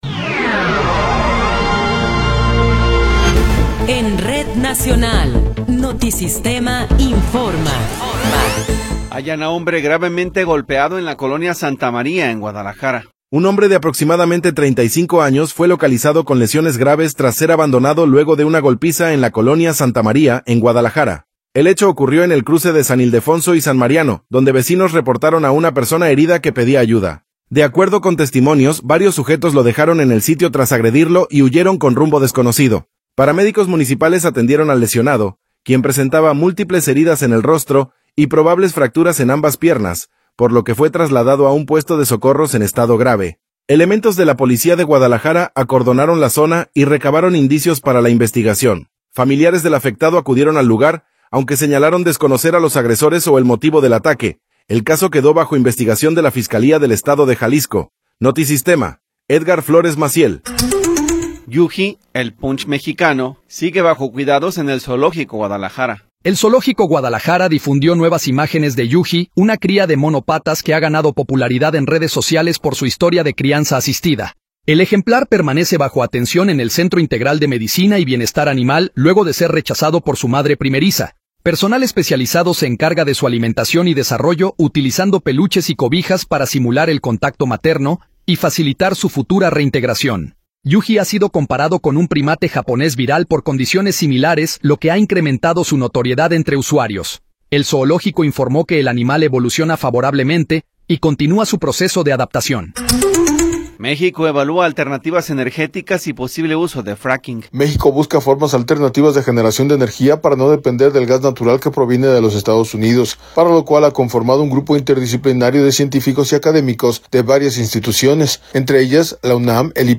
Noticiero 10 hrs. – 15 de Abril de 2026